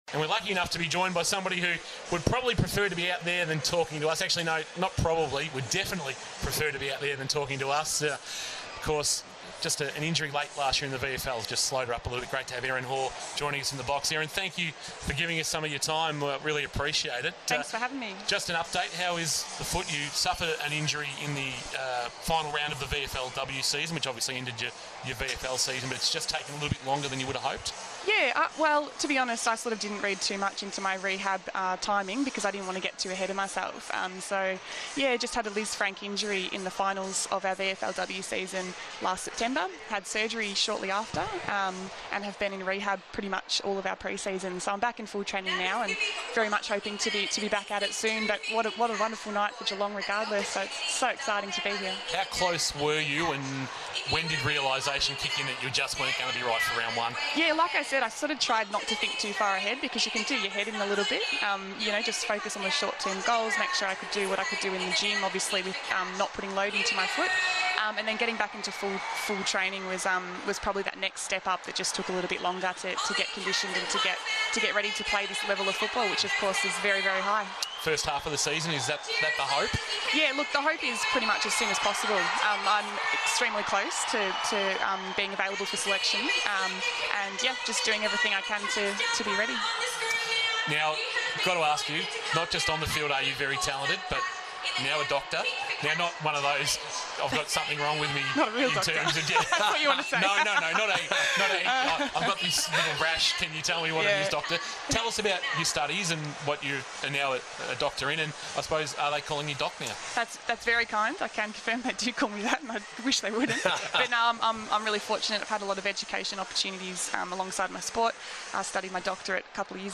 joined K rock Football at half-time of the Cats' historic first AFLW match.